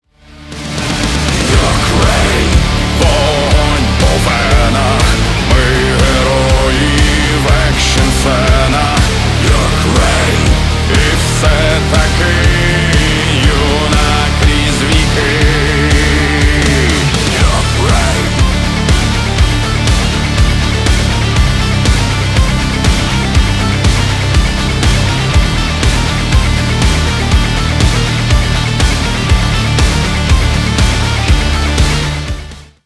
мужской вокал
Драйвовые
Cover
Industrial metal
Neue Deutsche Harte